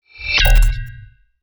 UI_SFX_Pack_61_3.wav